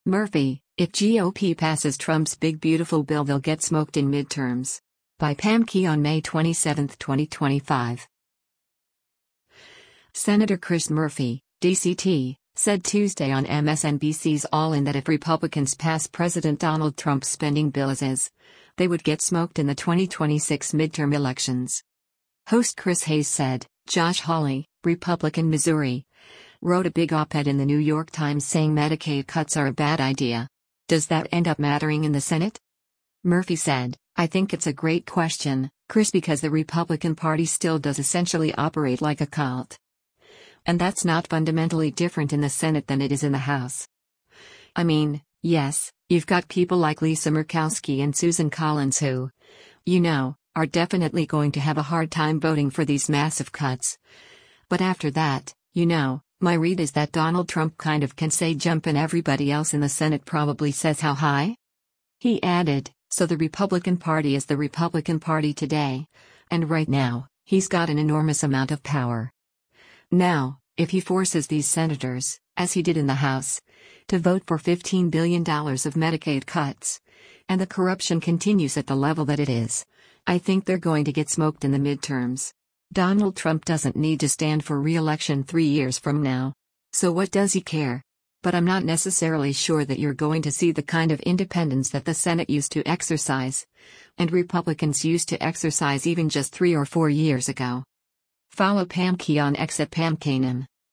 Senator Chris Murphy (D-CT) said Tuesday on MSNBC’s “All In” that if Republicans passed President Donald Trump’s spending bill as is, they would “get smoked” in the 2026 midterm elections.